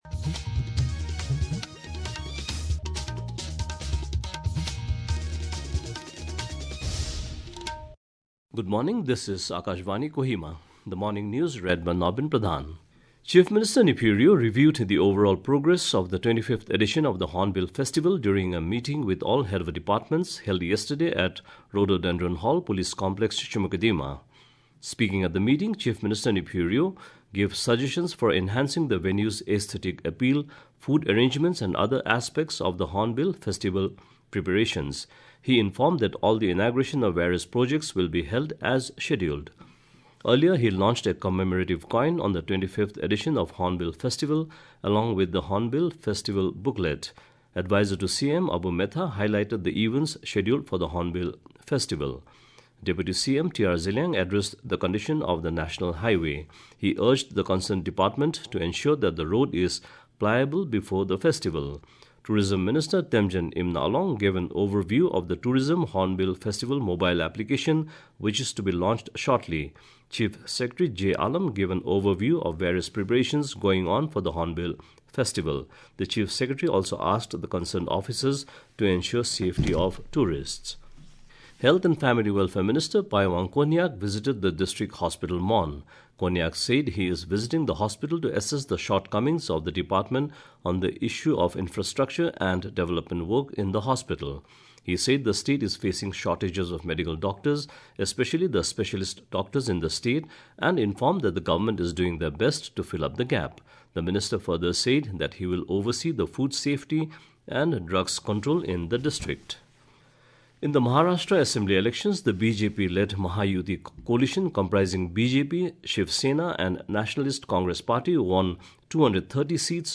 RNU-Kohima-Bulletin-Morning-Audio-English-News-0730-4.mp3